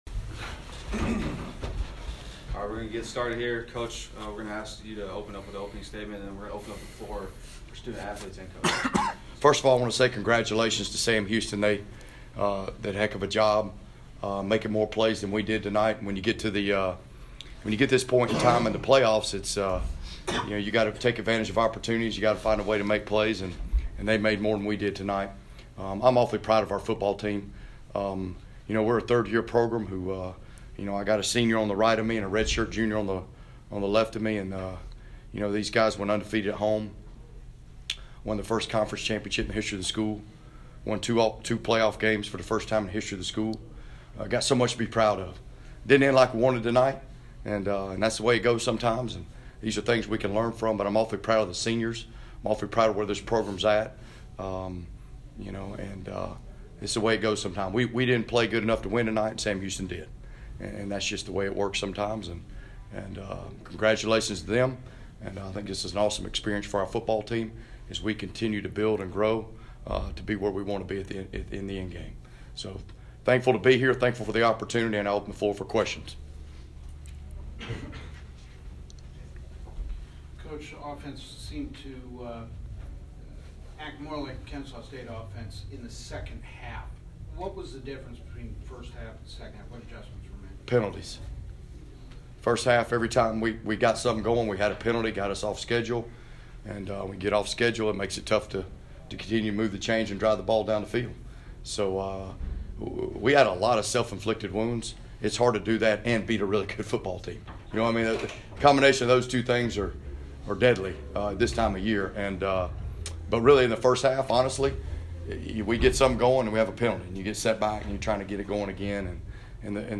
Post-game Press Conference (Audio)